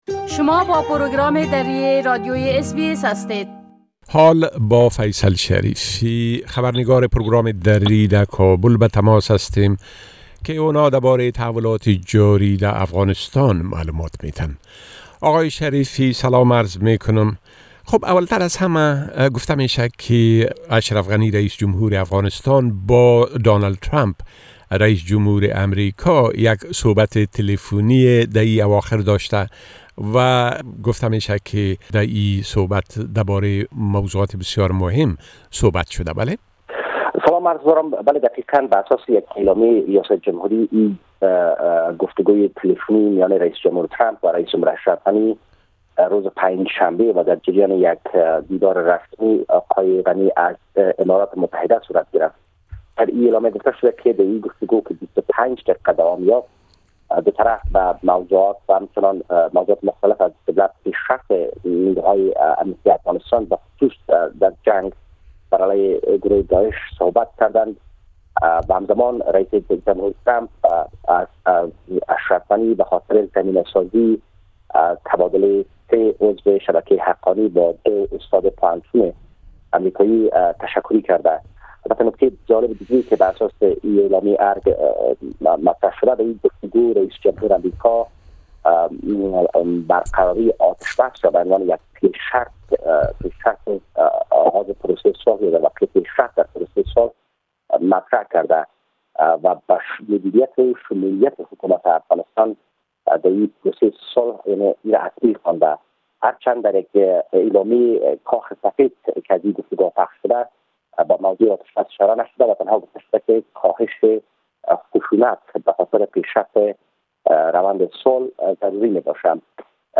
گرازش كامل خبرنگار ما در كابل دربارۀ تحولات تازه در ارتباط به بازشمارى آراى انتخابات رياست جمهورى و رويداد هاى مهم ديگر در افغانستان را در اينجا شنيده ميتوانيد.